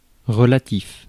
Ääntäminen
Ääntäminen France: IPA: /ʁə.la.tif/ Haettu sana löytyi näillä lähdekielillä: ranska Käännös 1. suhteline 2. relatiivne Suku: m .